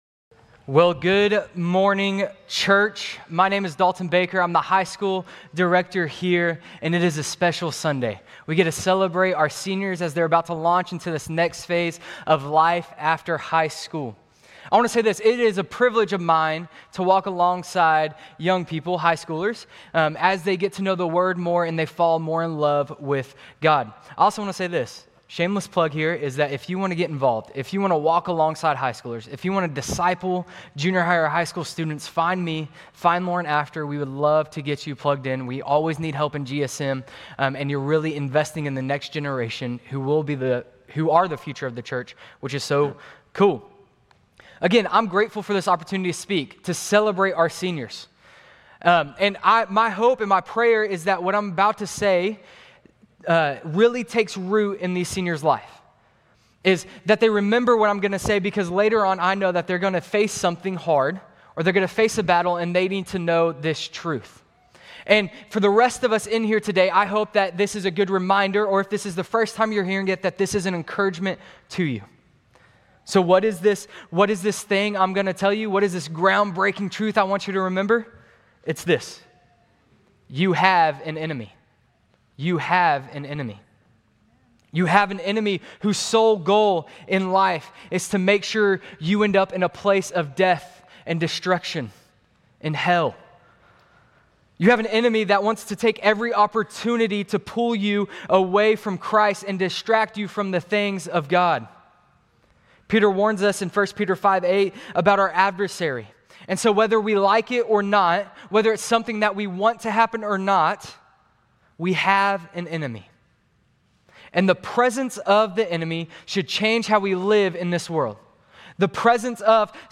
Grace Community Church Lindale Campus Sermons 5_4 Lindale Campus May 05 2025 | 00:21:38 1x 00:00 / 00:21:38 Subscribe Share RSS Feed Share Link Embed